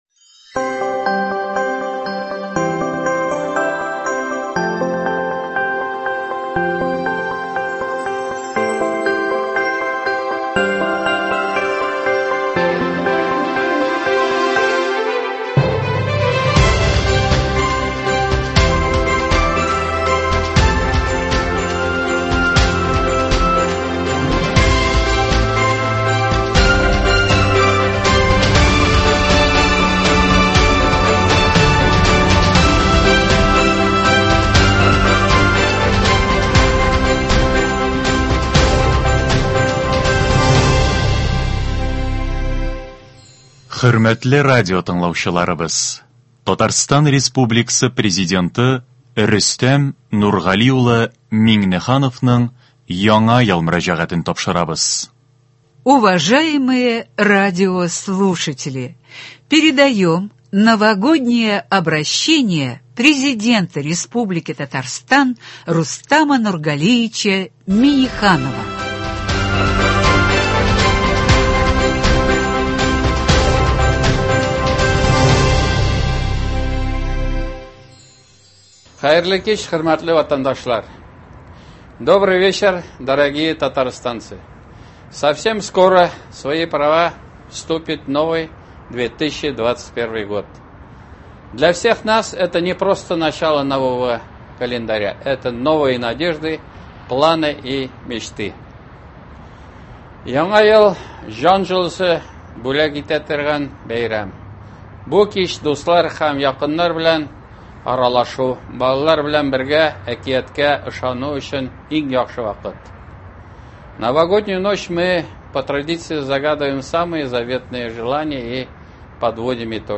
Татарстан Президенты Р.Н.Миңнехановның Яңа ел Мөрәҗәгате.
Новогоднее обращение Президента Республики Татарстан Р.Н.Минниханова.